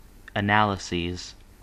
Uttal
Uttal US UK Okänd accent: IPA : /əˈnæl.ɪ.siːz/ IPA : /əˈnæl.ə.siːz/ IPA : /ˈæn.ə.laɪ.zɪz/ IPA : /ˈæn.ə.laɪ.zəz/ Ordet hittades på dessa språk: engelska Ingen översättning hittades i den valda målspråket.